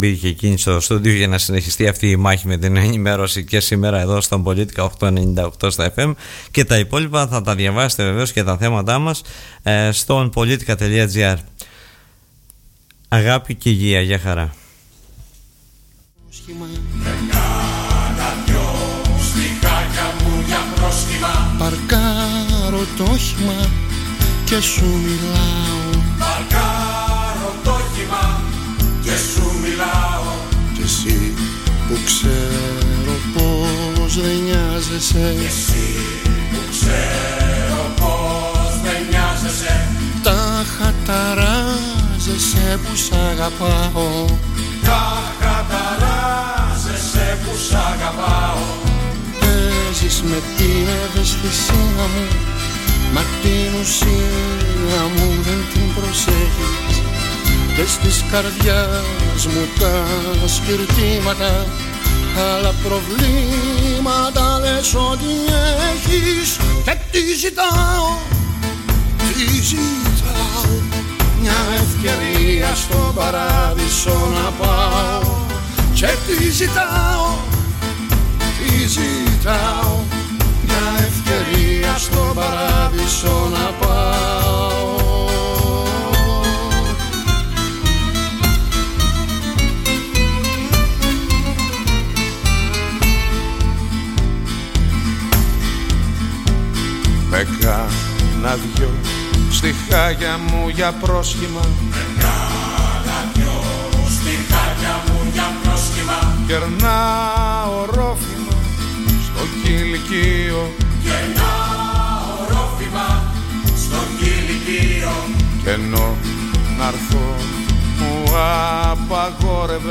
μίλησε στον Politica 89.8
ο πρώην Δήμαρχος Χερσονήσου Ζαχαρίας Δοξαστάκης